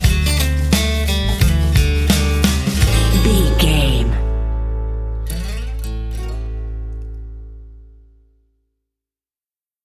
Ionian/Major
drums
acoustic guitar
banjo
bass guitar
percussion